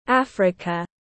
Africa /ˈæf.rɪ.kə/